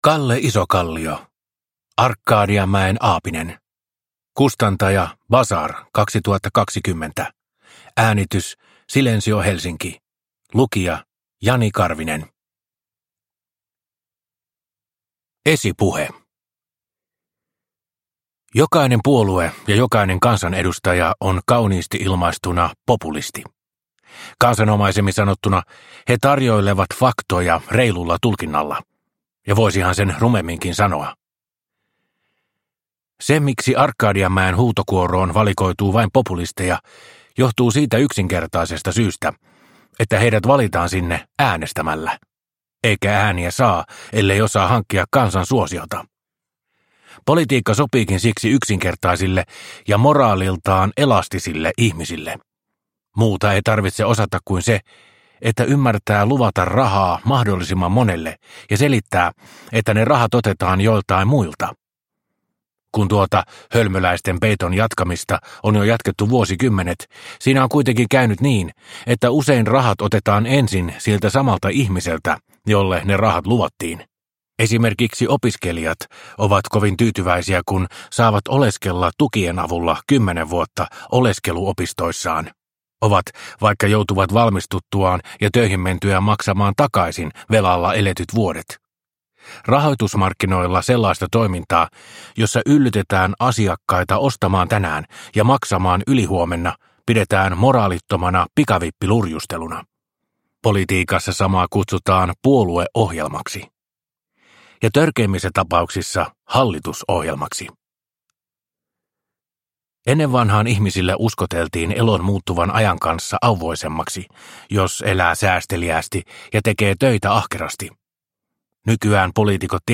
Arkadianmäen aapinen – Ljudbok – Laddas ner